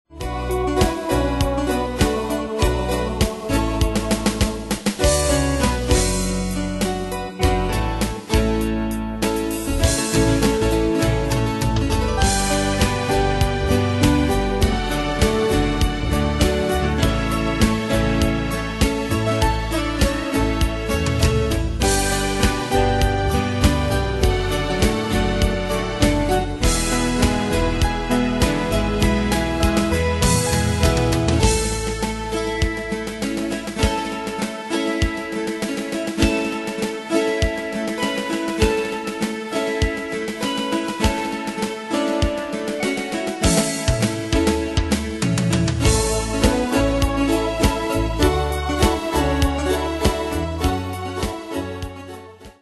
Style: PopAnglo Ane/Year: 1993 Tempo: 100 Durée/Time: 4.49
Danse/Dance: Ballade Cat Id.
Pro Backing Tracks